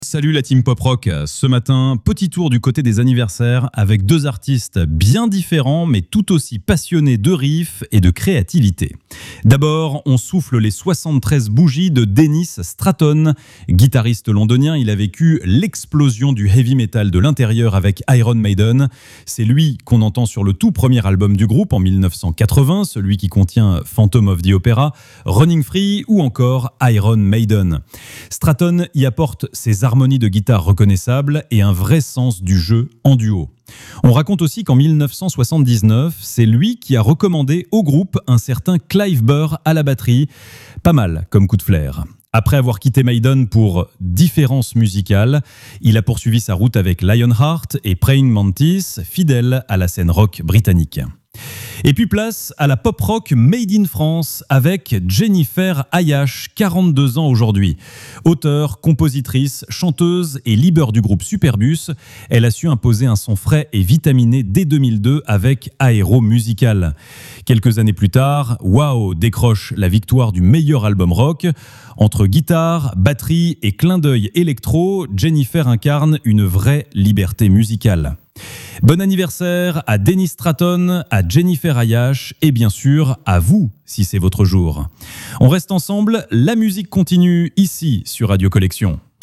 Une chronique vivante qui mêle souvenirs, anecdotes et découvertes pour un véritable voyage quotidien dans l’histoire des artistes préférés des fans de Pop Rock, des années 70 à aujourd’hui.